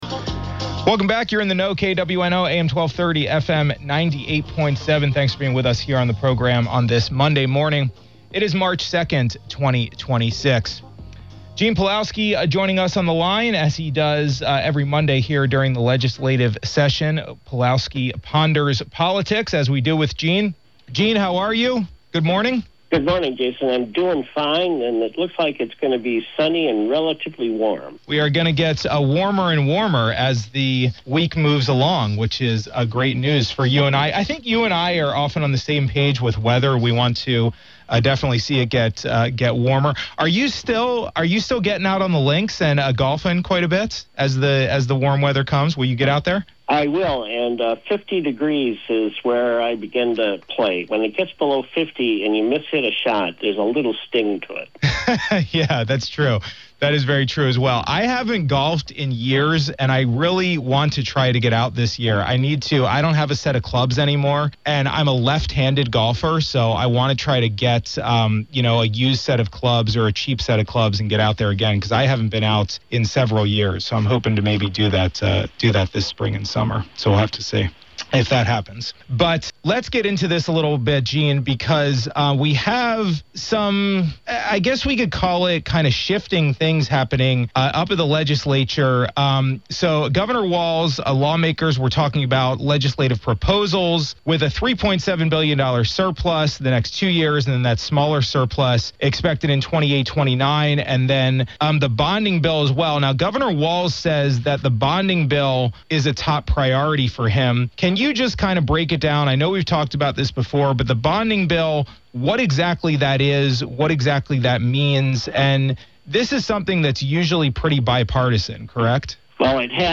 (KWNO)-Listen to our weekly conversation with Gene Pelowski on the happenings in St. Paul as the legislative session heats up.